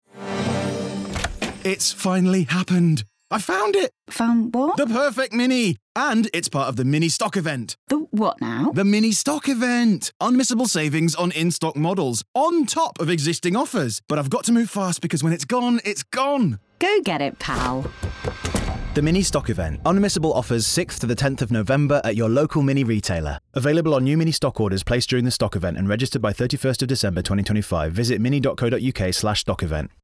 Male
I work in both northern British and neutral British accents with a charming, warm, confident, trustworthy, and friendly voice.
Mini - National Radio Ad
1202Mini_Stock_Event__Radio_Ad_.mp3